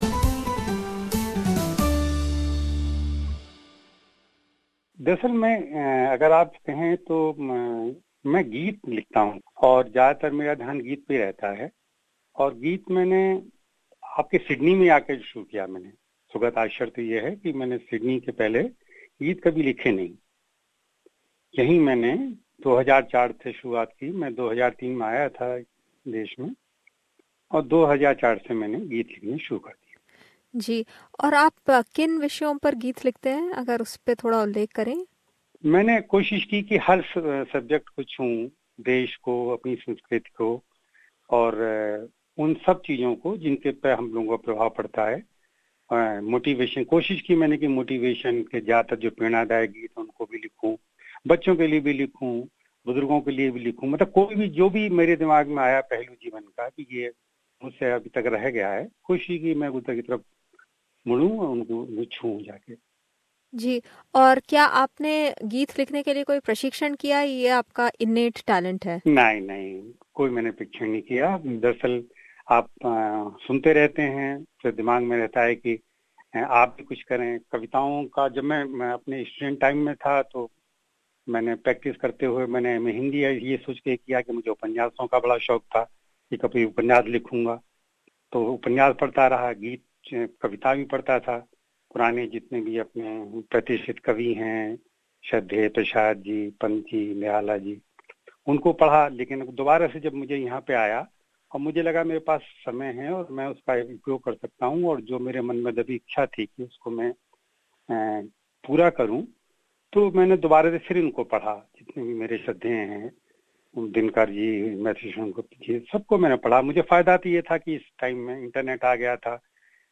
Tune in to listen to this free flowing chat...